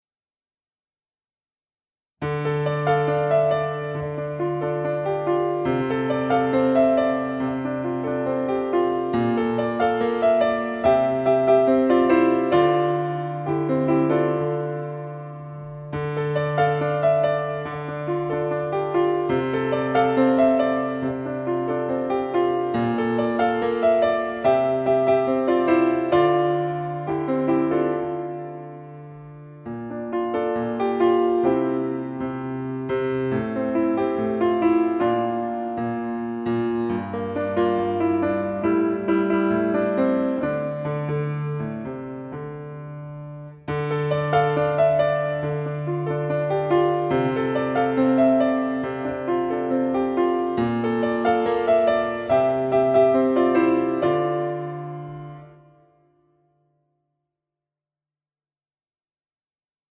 Besetzung: Instrumentalnoten für Klavier
Pedal
Moderne Rhythmen wie Synkopen und ternäre Spielweise